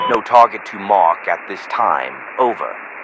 Radio-jtacSmokeNoTarget1.ogg